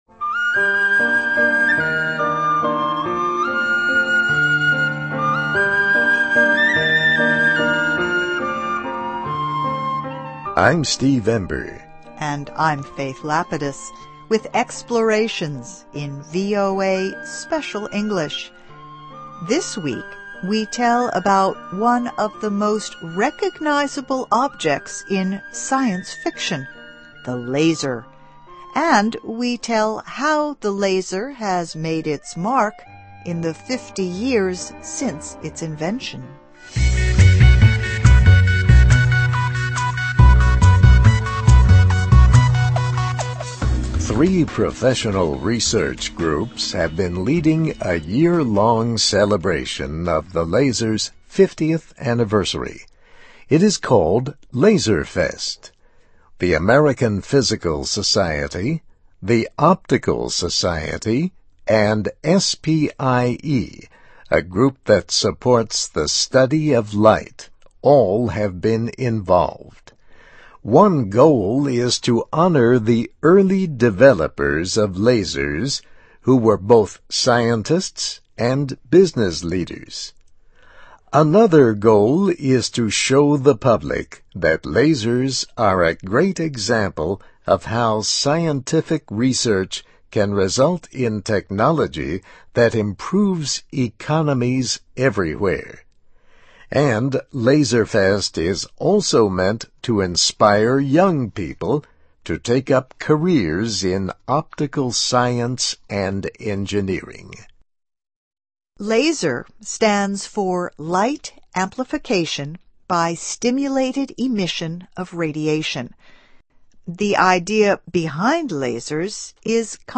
English Listening Practice.